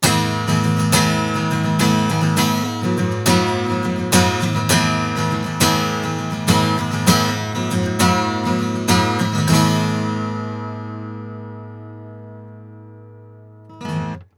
実際の録り音
アコースティック・ギター
SM57-アコギ.wav